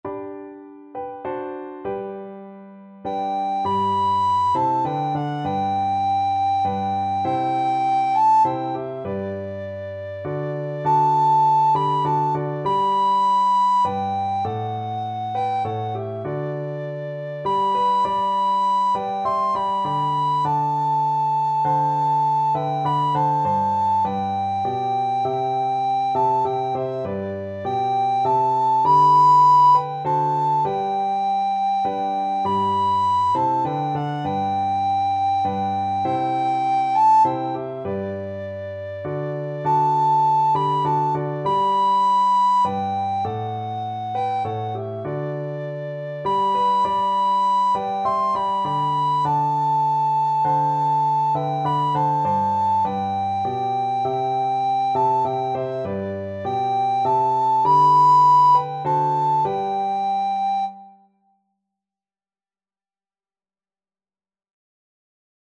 3/4 (View more 3/4 Music)
Classical (View more Classical Recorder Music)